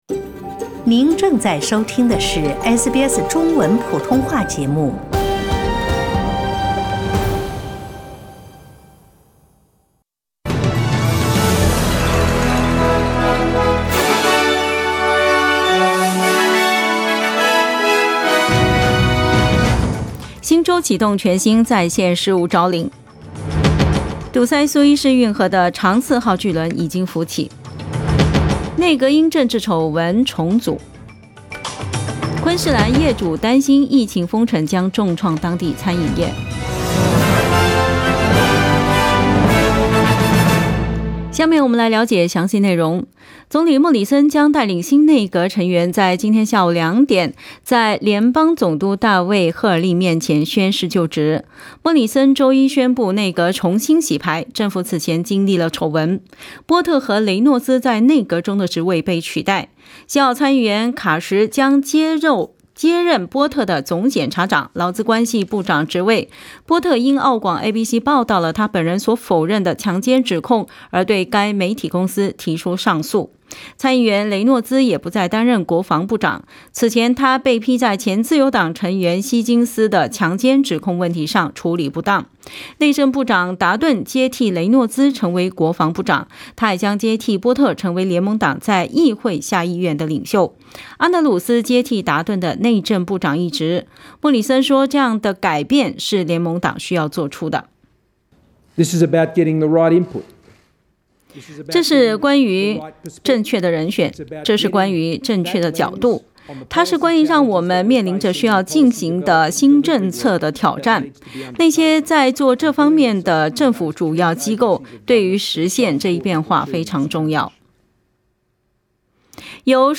SBS 早新聞（3月30日）